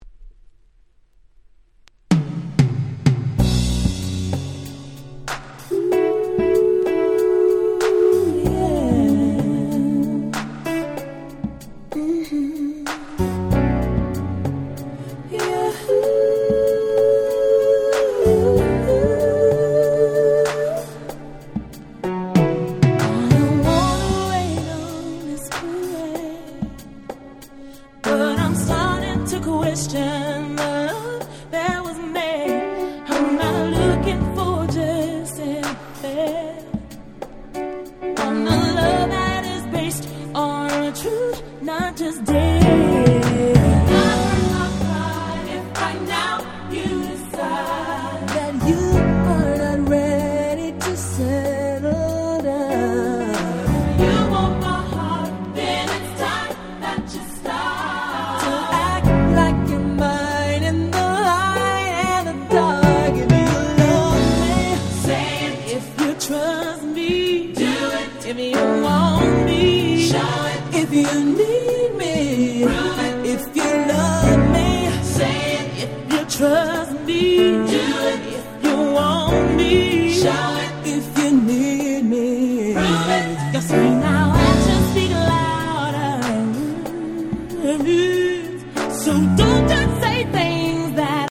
はBeat無しでハーモニーとメロディだけでグイグイ引っ張る最高に甘いRemix !!